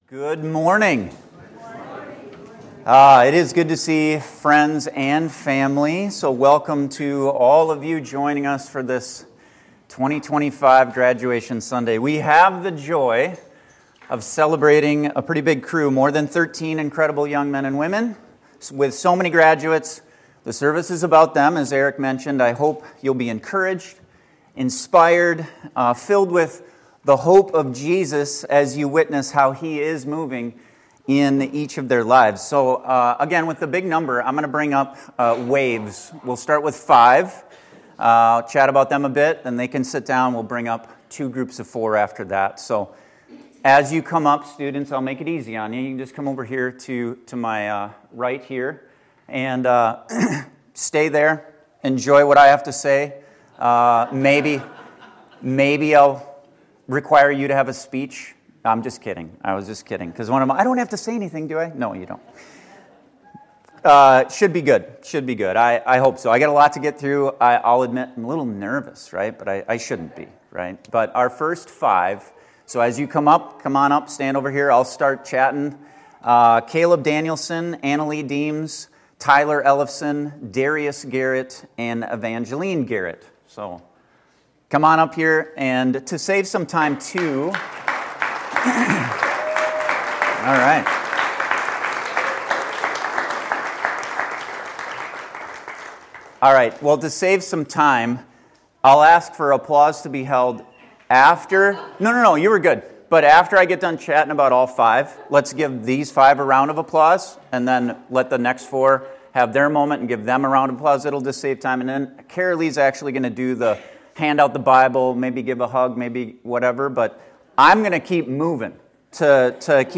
A special service as we honored this year’s graduating seniors.